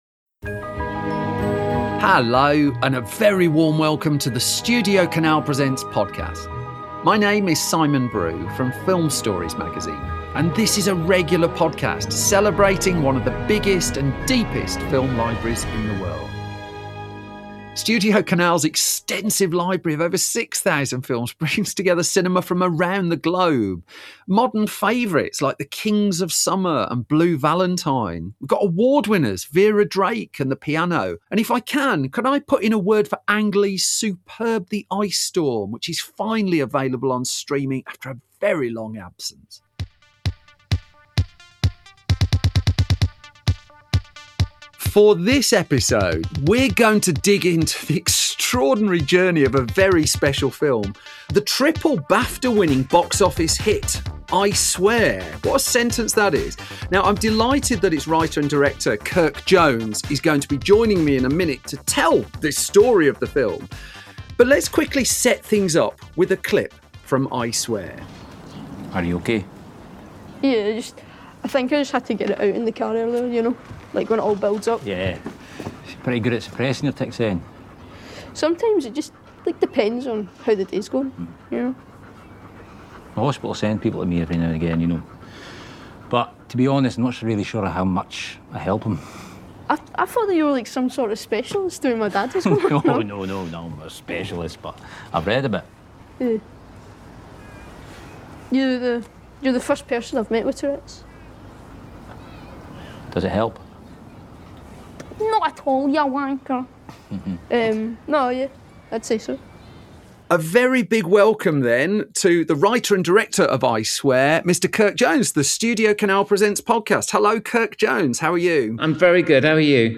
The pair chat about the film, and also bring the similarly underappreciated Pool Of London into the conversation.